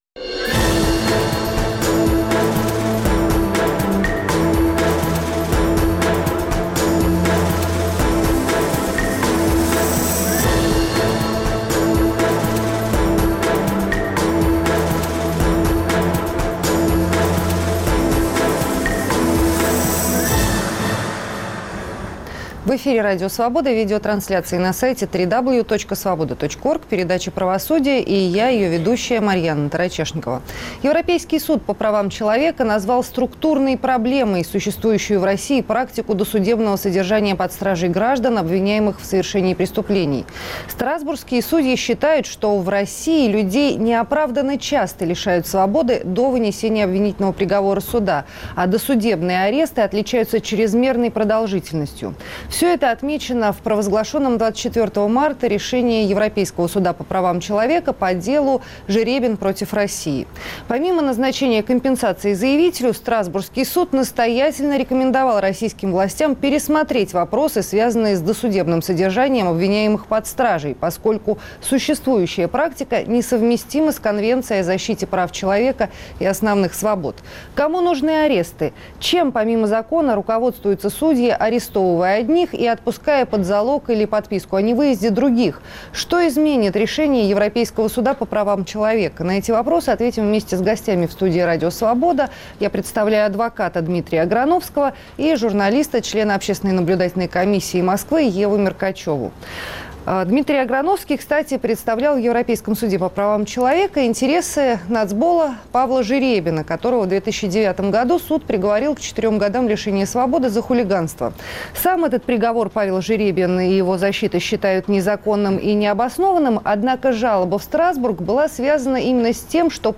Страсбург отчитал Россию. В студии